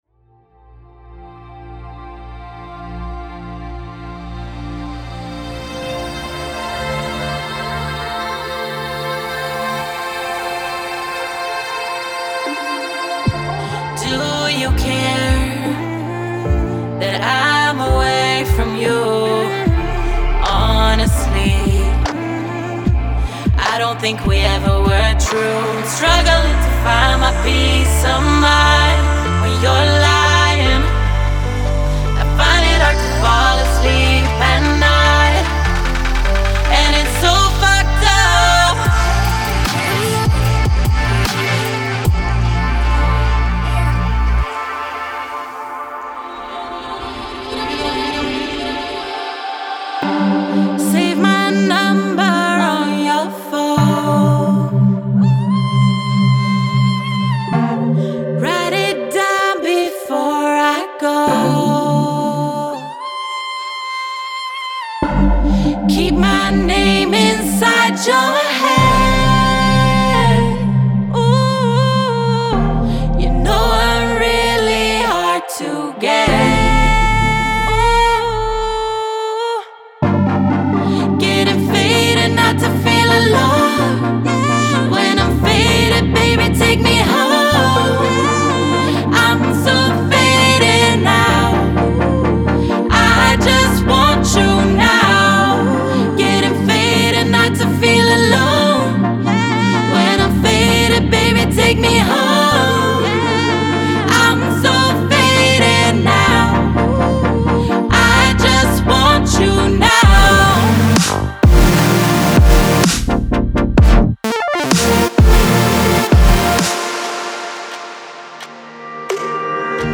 Genre:EDM
シンセループやピアノループは即座にメロディックな動きと感情的な深みをトラックに加えます。
デモサウンドはコチラ↓